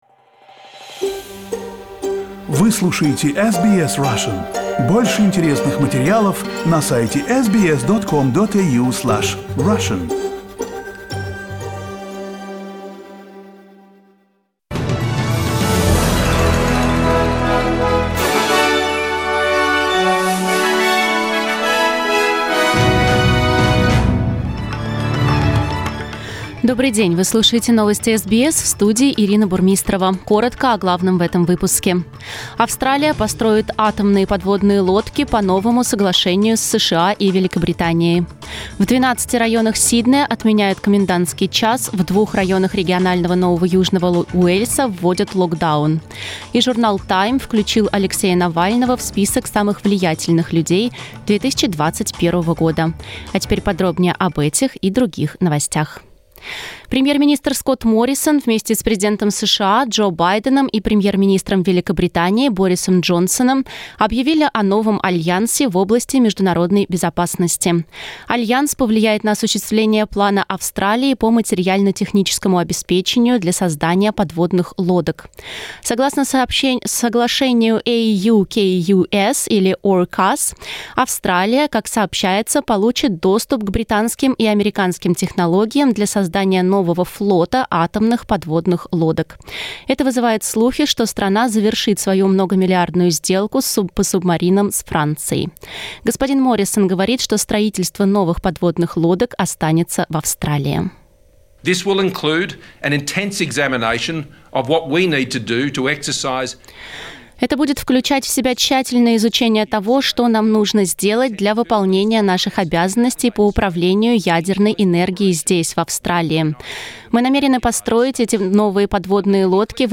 SBS news in Russian - 16.09